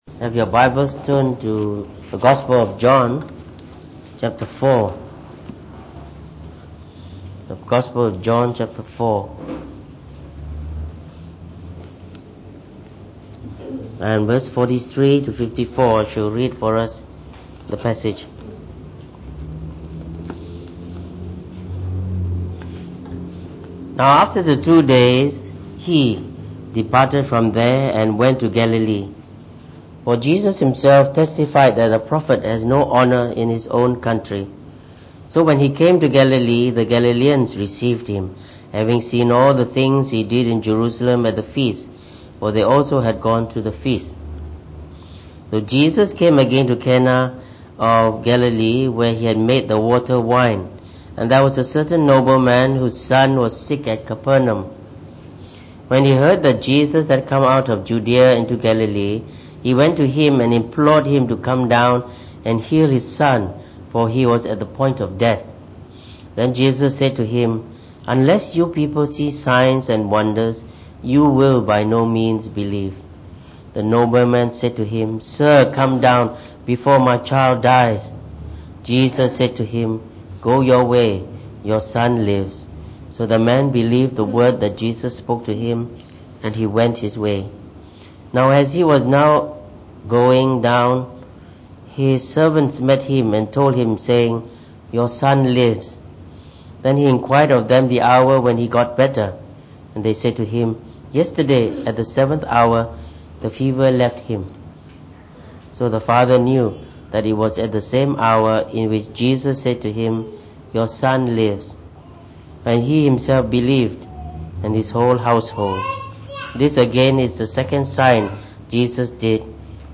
Preached on the 16th September 2018.